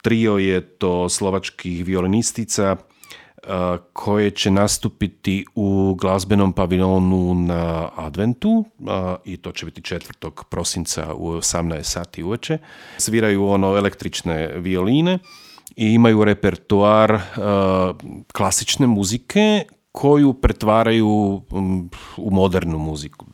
Slovački veleposlanik Peter Susko u razgovoru za Media servis je najavio koncert violinistica Xplosion.